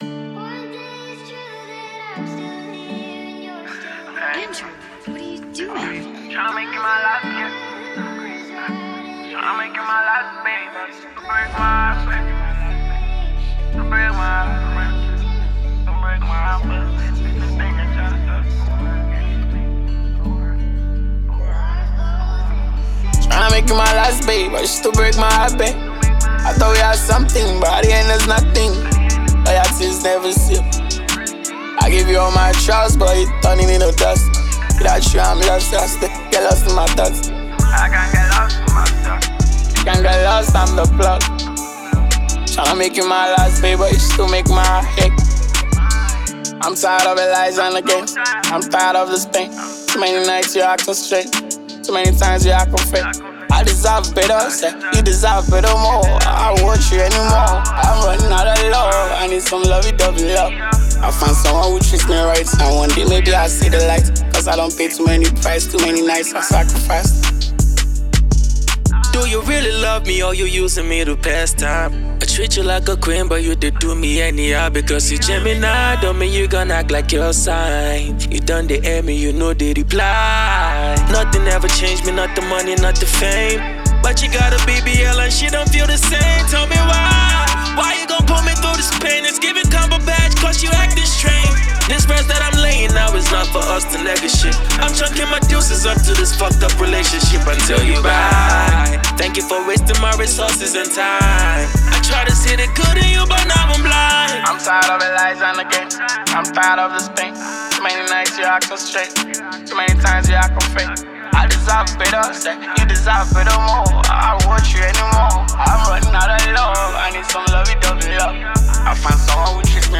Nigerian hip-hop
stands out for its bold lyrics and energetic delivery